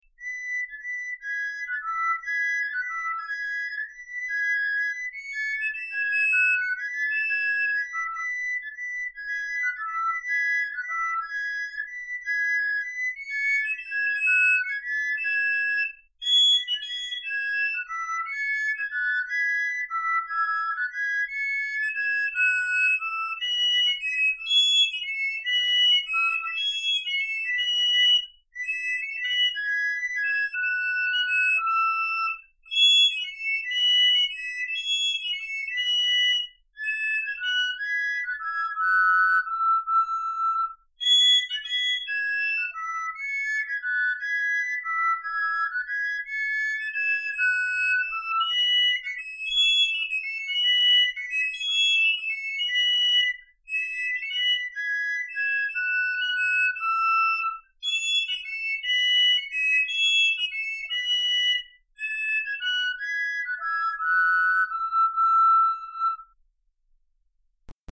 Canaries_Stereo.mp3